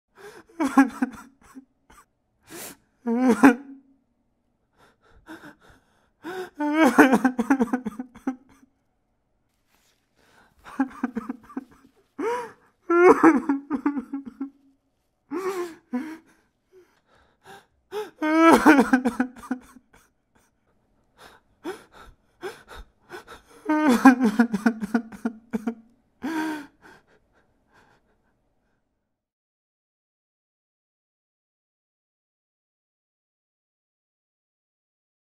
دانلود صدای گریه مرد 2 از ساعد نیوز با لینک مستقیم و کیفیت بالا
جلوه های صوتی